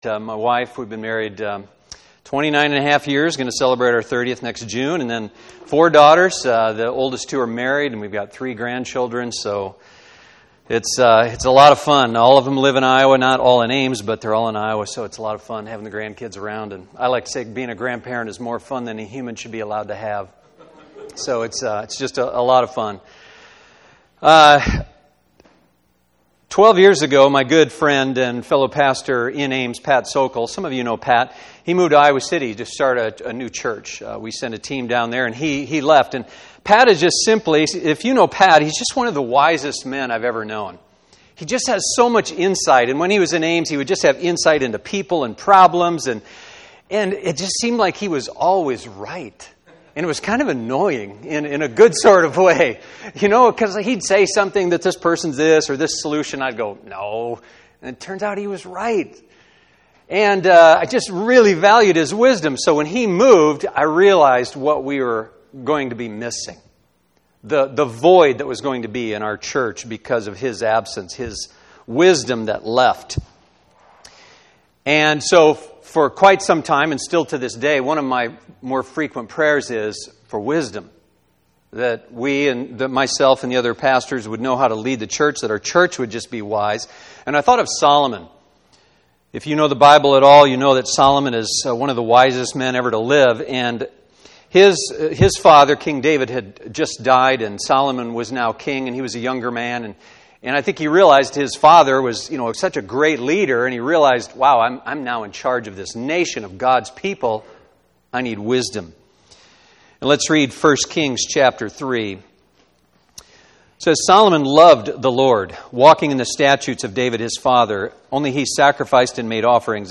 How We Hear From God Service Type: Sunday Morning %todo_render% « Vocation